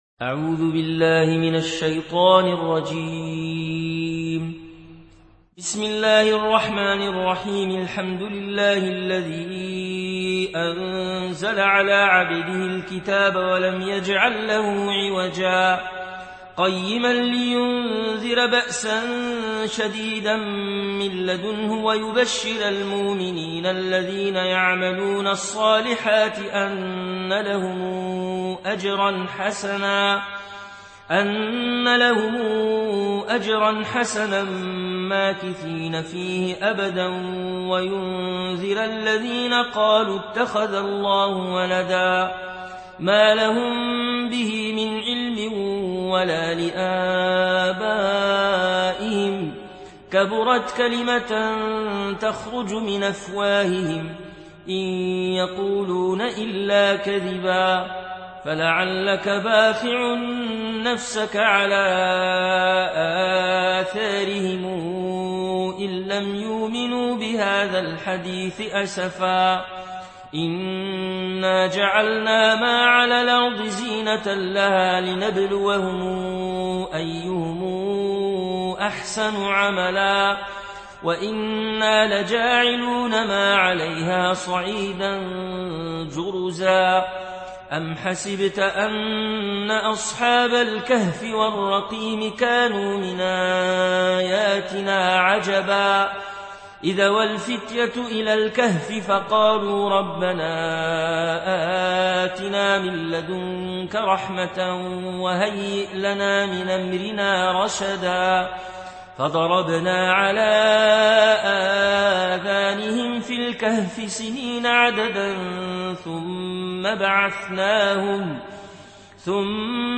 Warsh থেকে Nafi